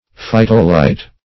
Meaning of phytolite. phytolite synonyms, pronunciation, spelling and more from Free Dictionary.
phytolite.mp3